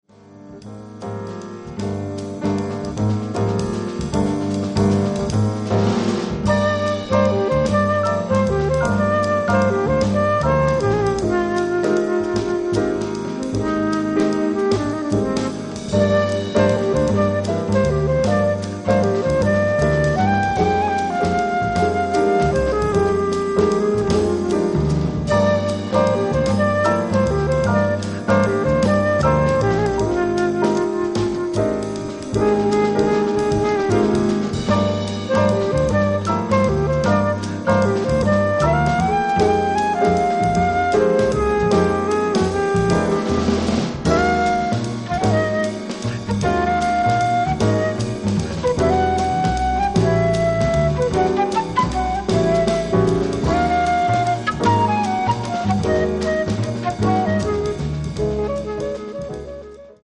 Beautiful Jazz Treats